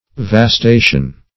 Search Result for " vastation" : The Collaborative International Dictionary of English v.0.48: Vastation \Vas*ta"tion\, n. [L. vastatio, fr. vastare to lay waste, fr. vastus empty, waste.]